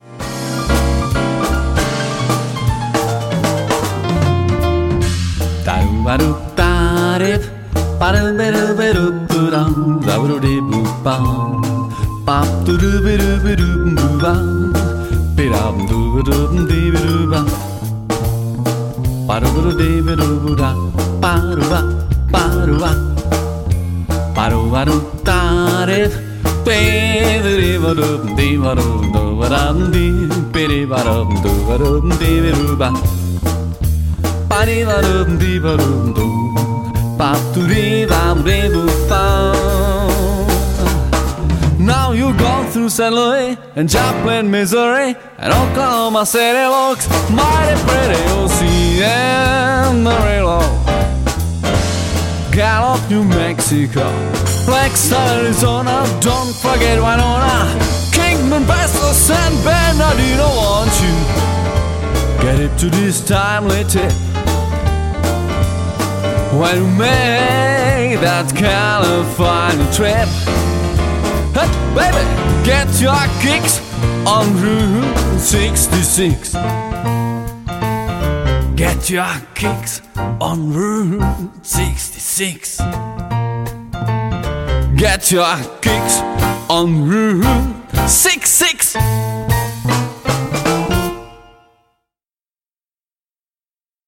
Hrajeme živý jazz, swing